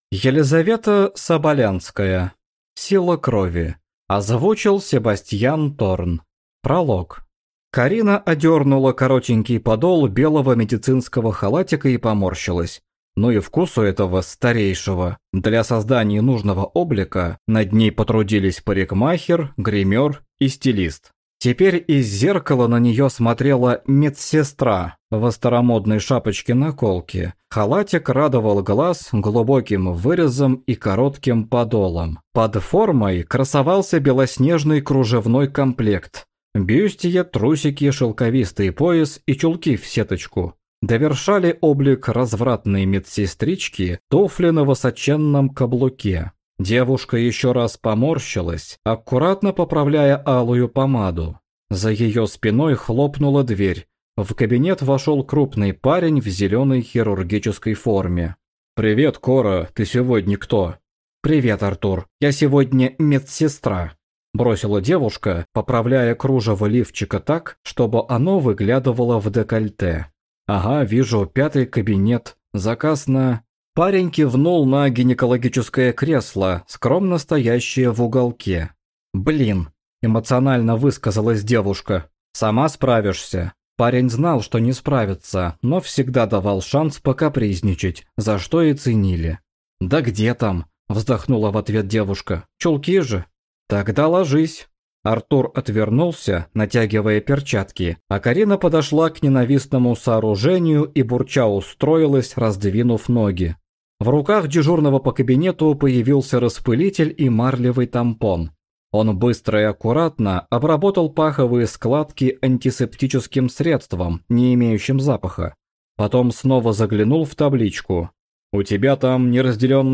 Аудиокнига Сила крови | Библиотека аудиокниг